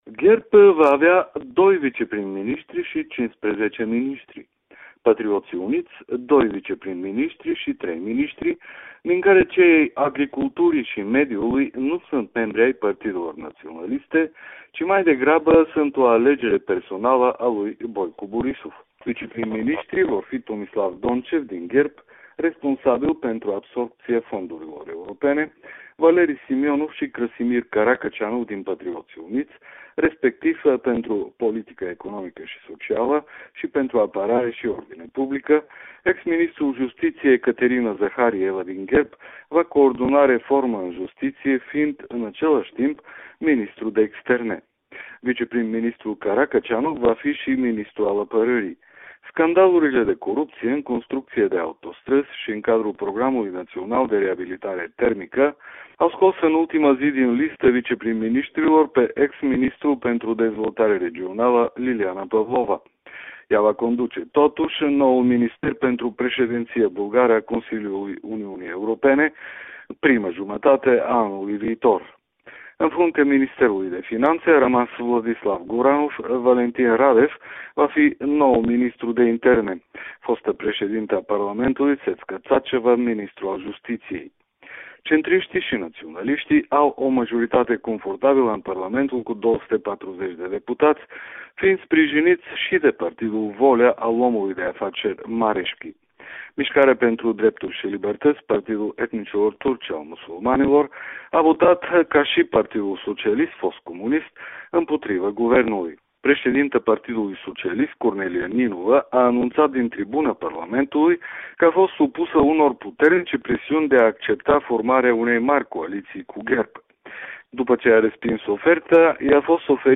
Corespondența zilei de la Sofia